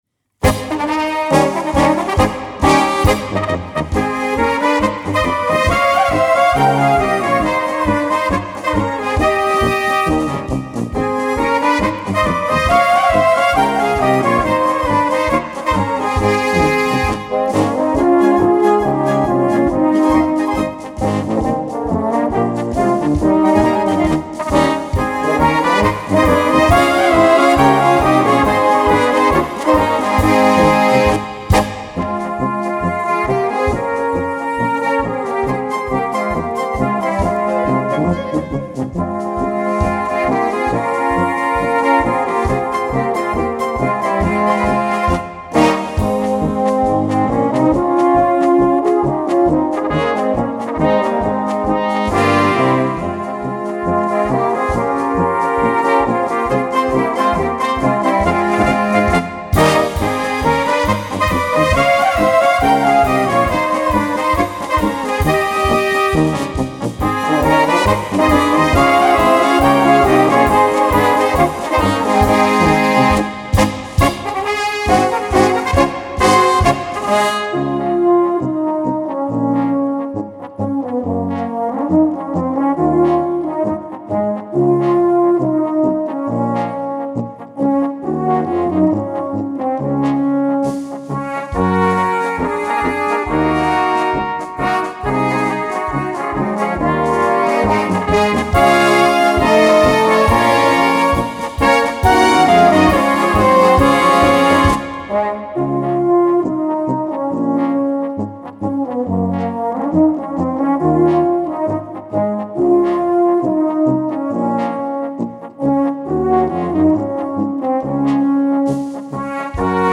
Blasorchester Noten / Blasmusik Noten:
Marsch